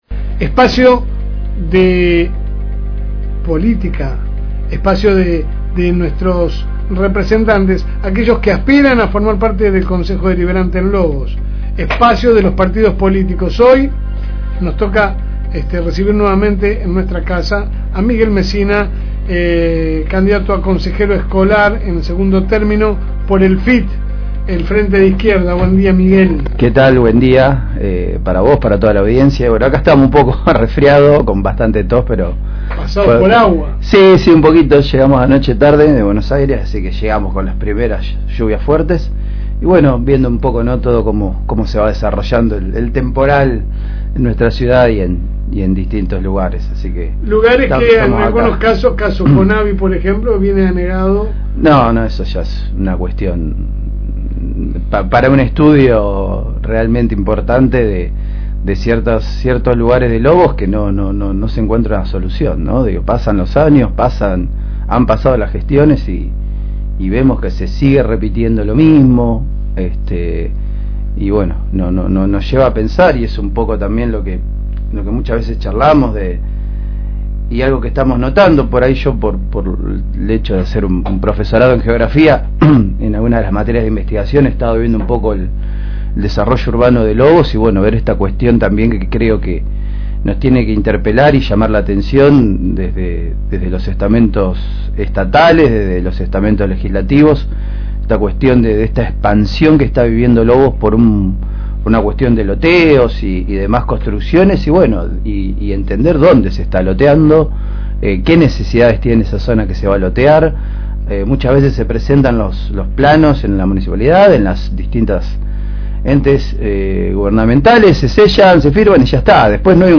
AUDIO | Elecciones 2025 | Espacio Frente de Izquierda de los Trabajadores (FIT) – FM Reencuentro